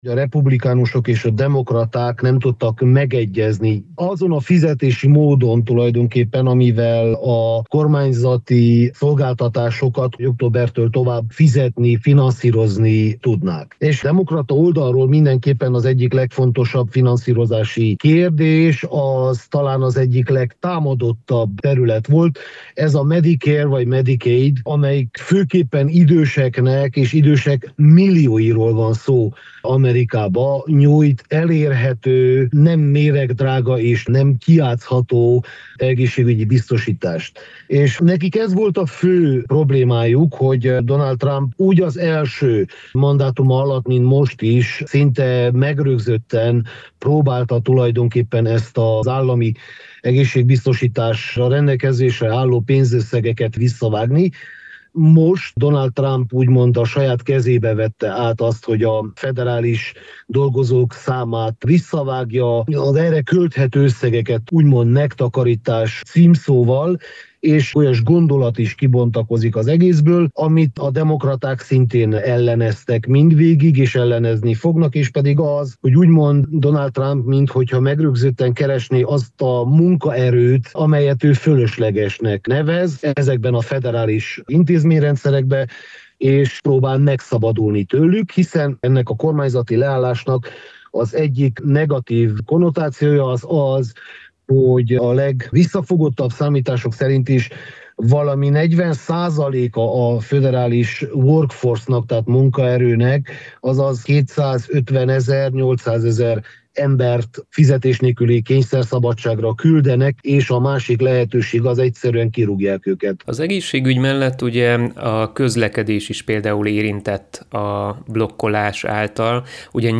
Leállt a kormányzat, ha a költségvetési válság tovább tart, tömeges elbocsátások várhatók. Elemzővel beszélgettünk a lehetséges kifutásról.